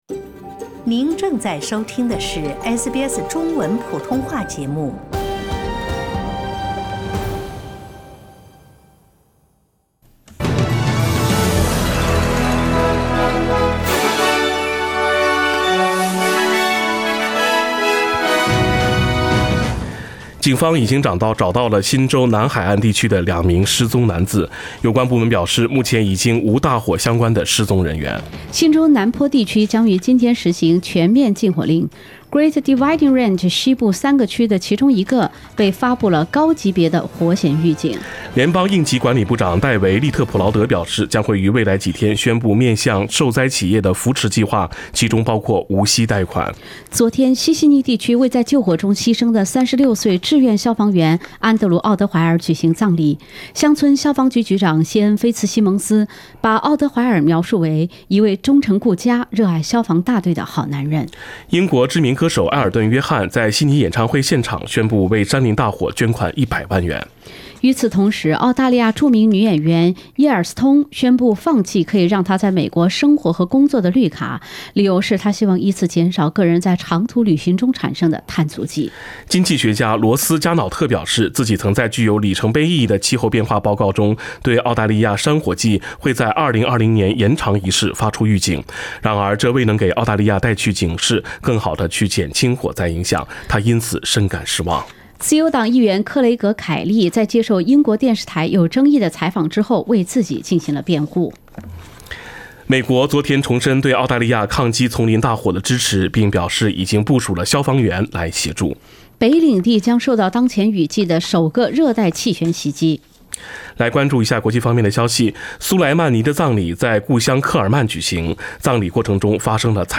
SBS 早新闻 （1月8日）